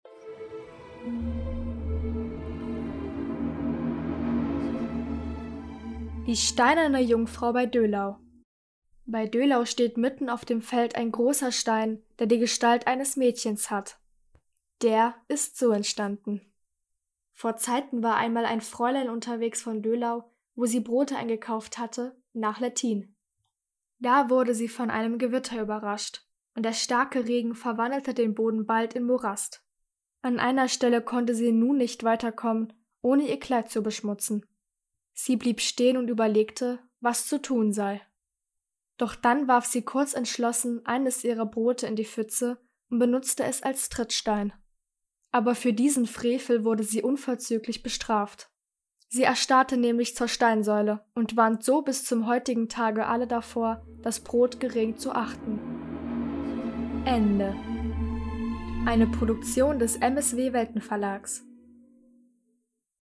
Eine Sage aus Halle (Saale) vorgelesen von der Salzmagd des